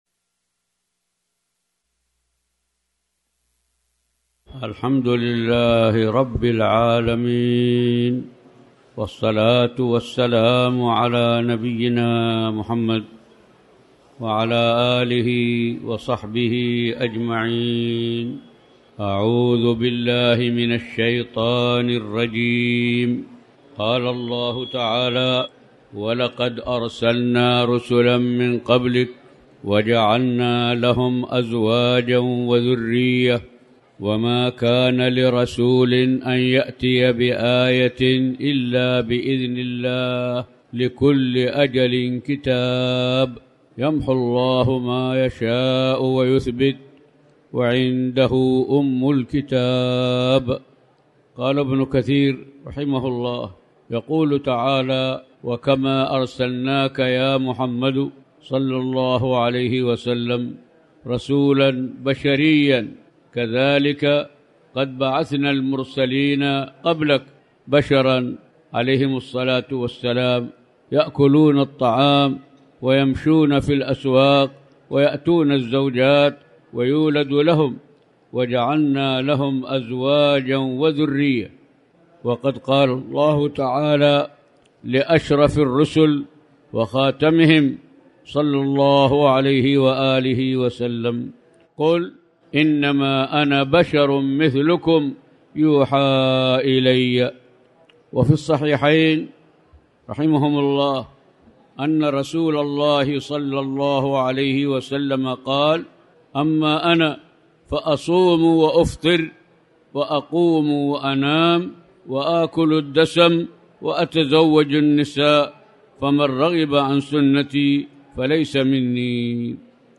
تاريخ النشر ٩ ربيع الأول ١٤٤٠ هـ المكان: المسجد الحرام الشيخ